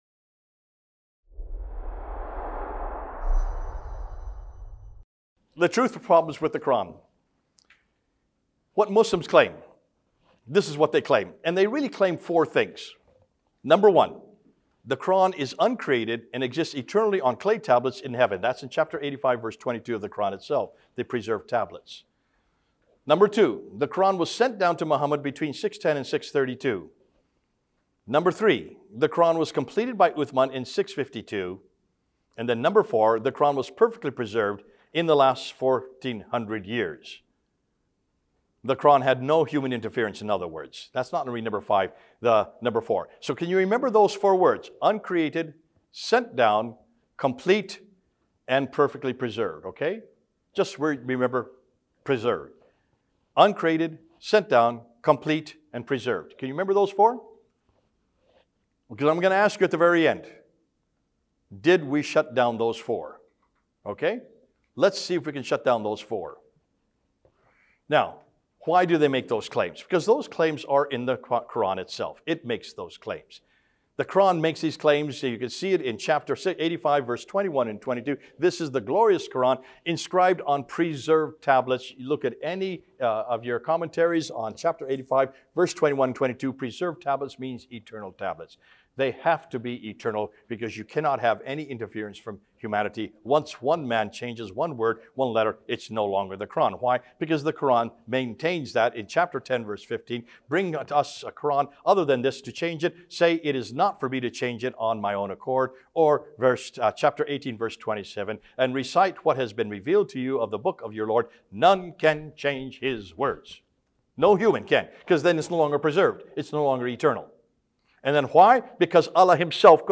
This series of three talks addresses the historical evidence and formation of the Qur’an.
Event: ELF Pre-Forum Seminar